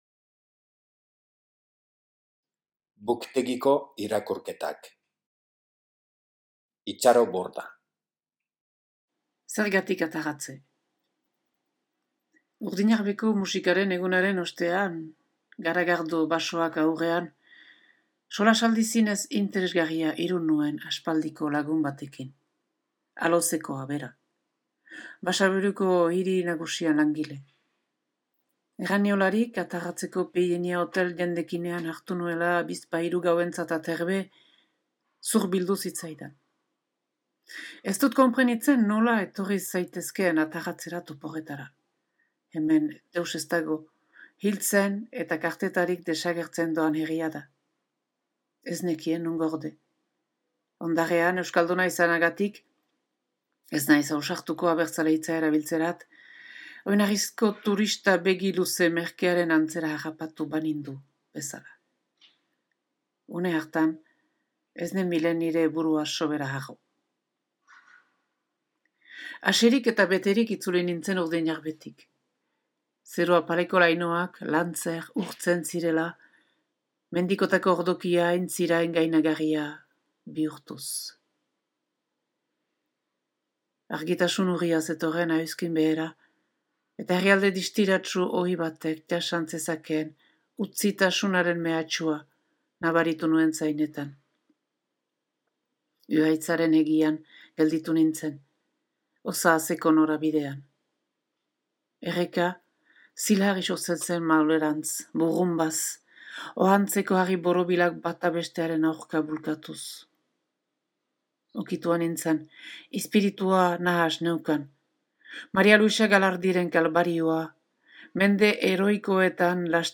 Itxaro Bordak bere artikulua irakurri digu.